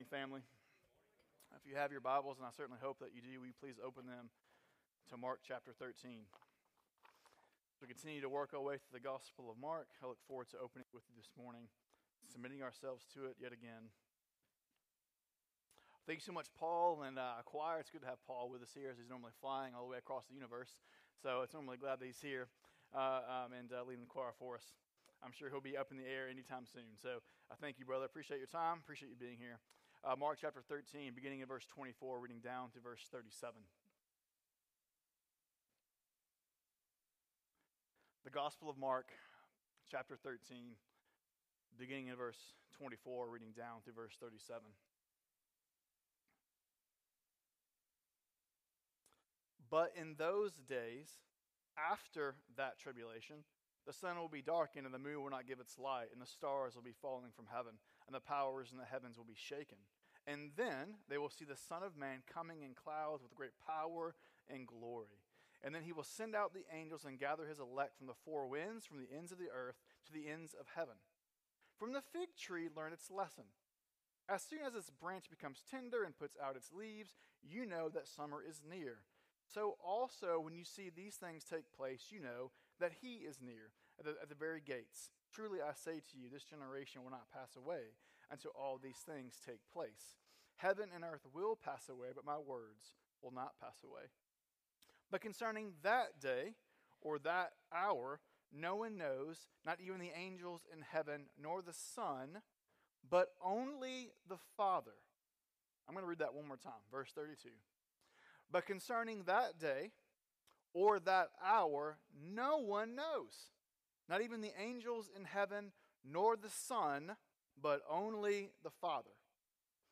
Sermons | Hermon Baptist Church